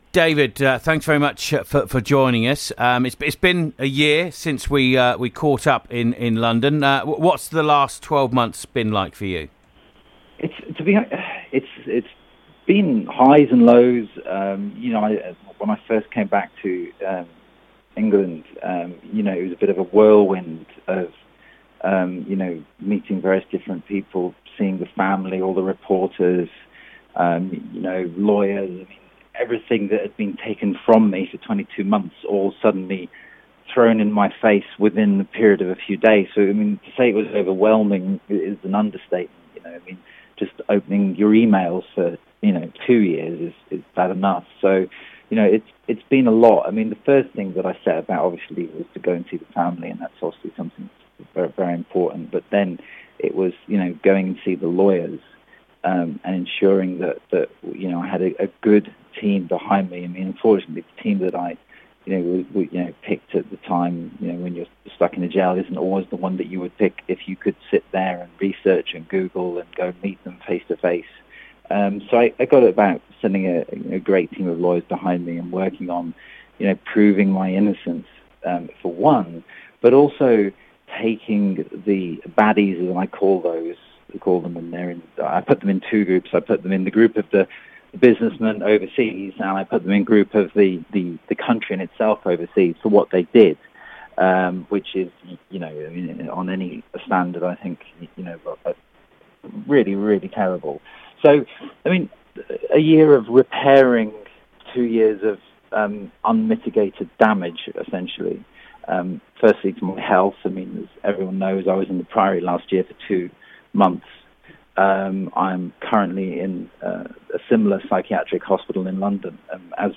An exclusive interview